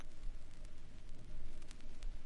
黑胶唱片的声音 " 表面噪音
描述：黑胶唱片表面噪音
标签： 表面噪声 乙烯基 记录
声道立体声